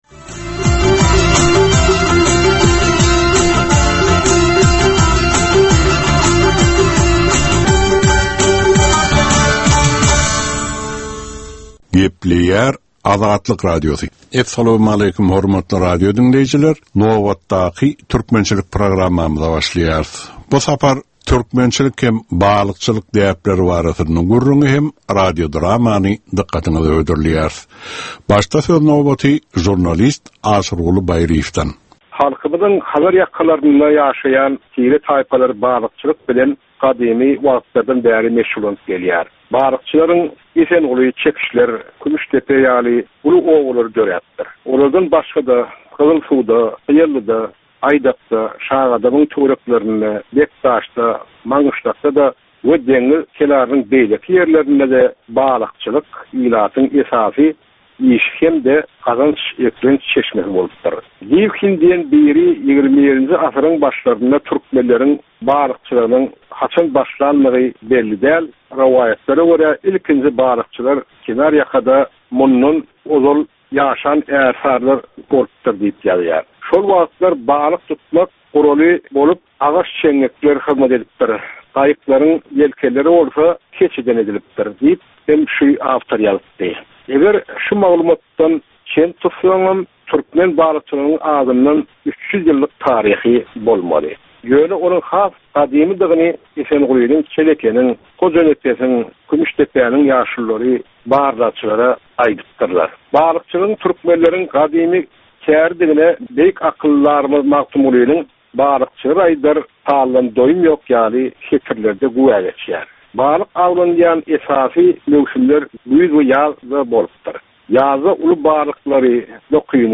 Türkmen halkynyň däp-dessurlary we olaryň dürli meseleleri barada 10 minutlyk ýörite gepleşik. Bu programmanyň dowamynda türkmen jemgyýetiniň şu günki meseleleri barada taýýarlanylan radio-dramalar hem efire berilýär.